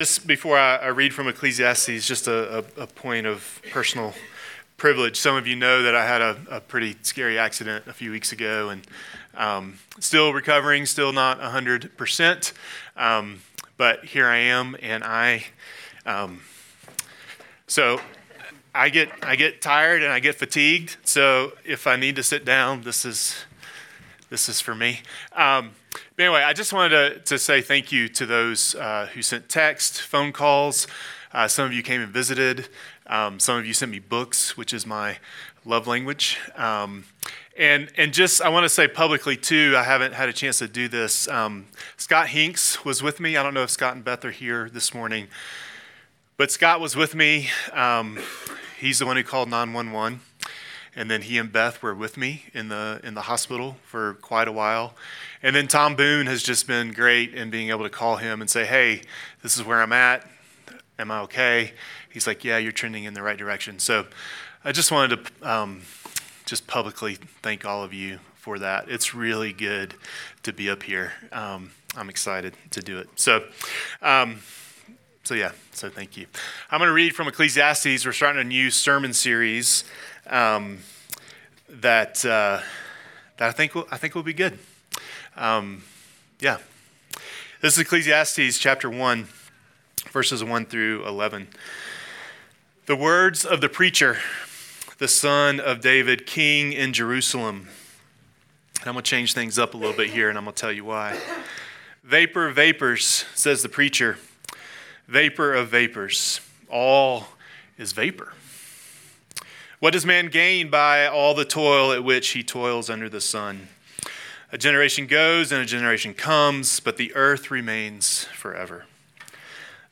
City Pres ABQ Sermons